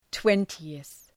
Προφορά
{‘twentııɵ}
twentieth.mp3